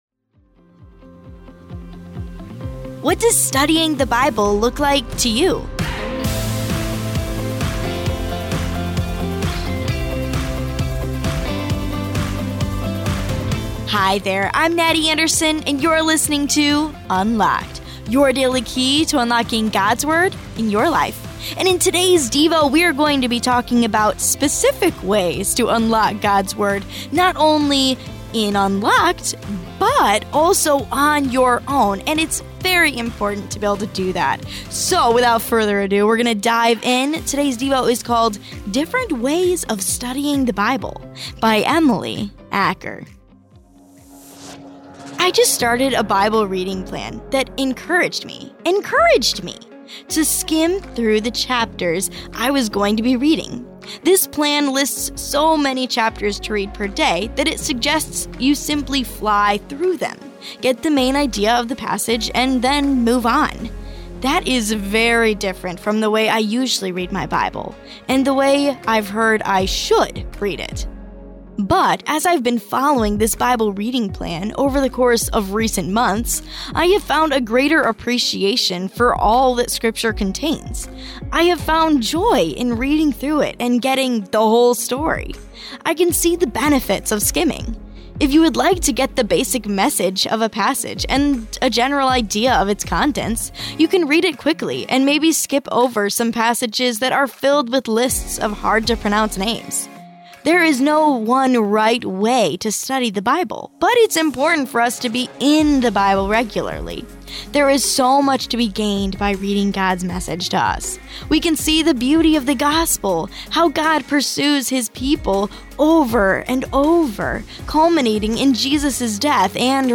Unlocked is a daily teen devotional, centered on God’s Word. Each day’s devotion—whether fiction, poetry, or essay—asks the question: How does Jesus and what He did affect today’s topic?